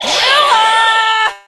jackie_drill_ulti_vo_04.ogg